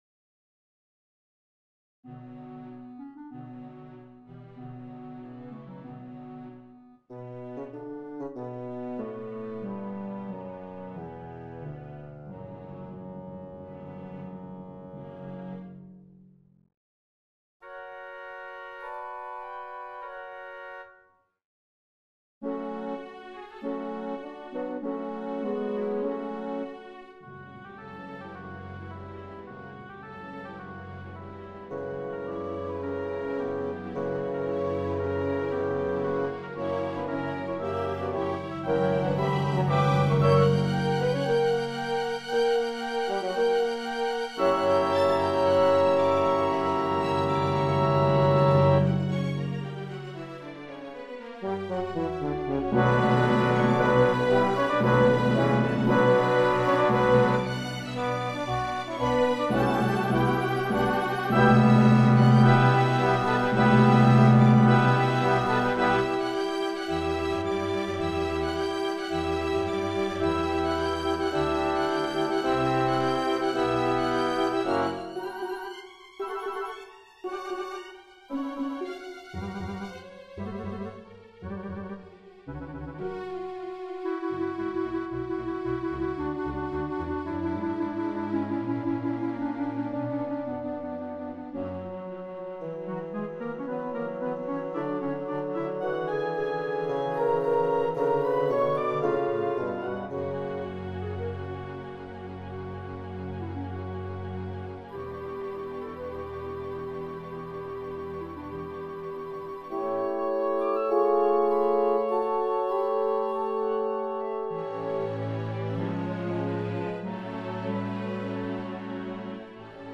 cello_concerto1_GPO.mp3